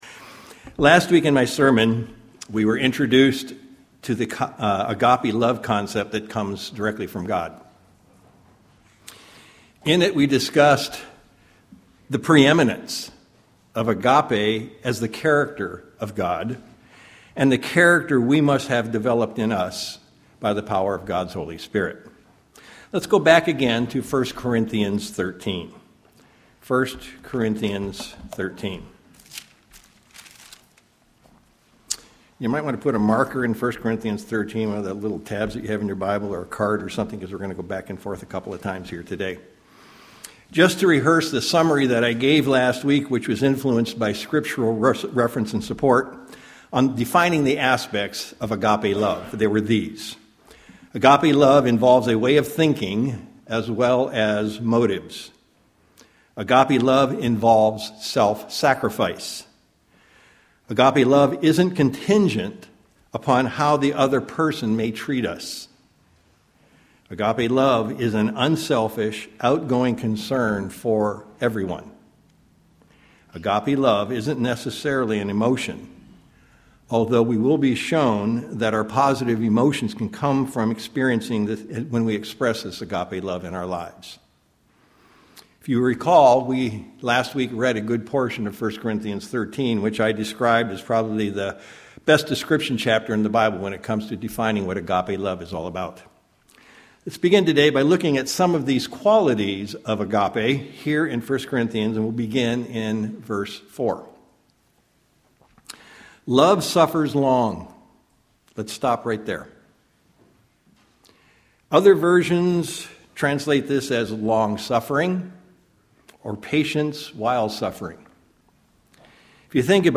In this sermon we continue the review of the aspects, or characteristics of Agape love, the true love of god that can only come from His Holy Spirit, as Paul outlined it in 1 Corinthians 13.
Given in Sacramento, CA